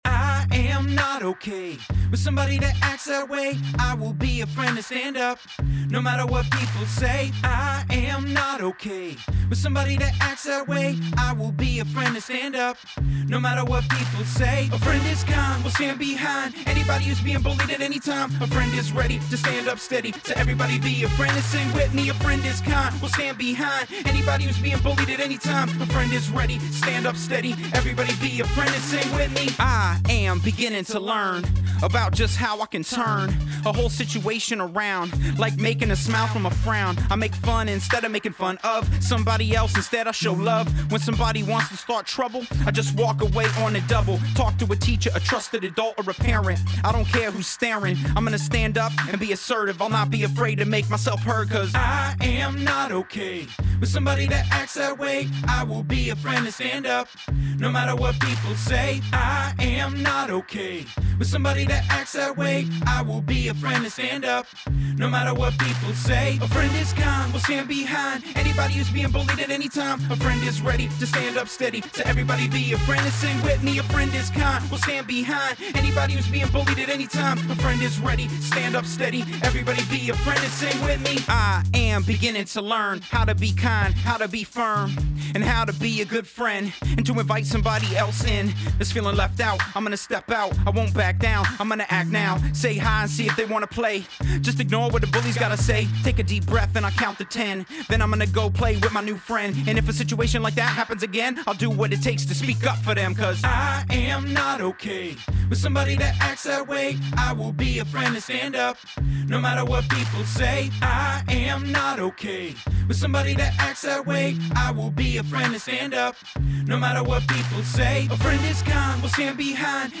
“Stand Up” is a fun, high energy song that talks about being a good friend and standing up for those who are bullied.